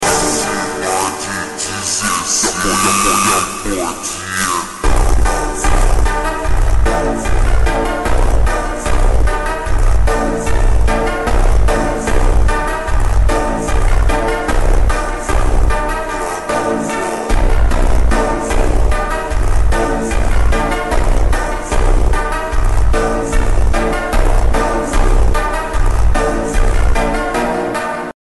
(Hyper Slowed Reverb)